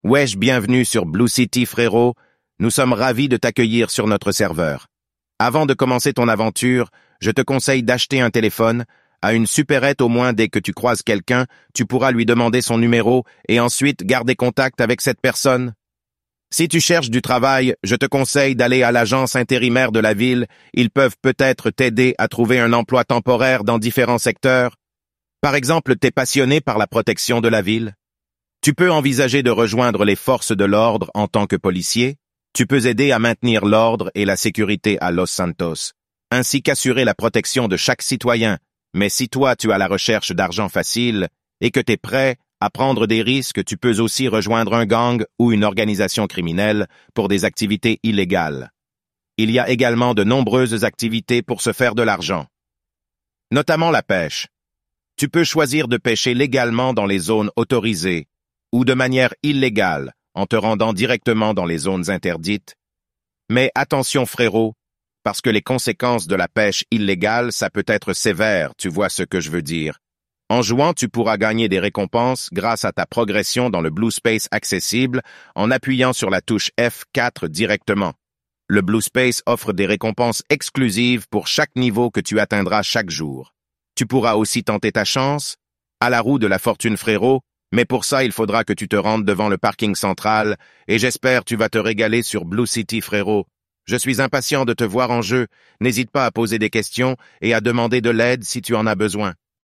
synthesized audio 1.mp3
synthesizedaudio1.mp3